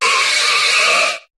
Cri de Scobolide dans Pokémon HOME.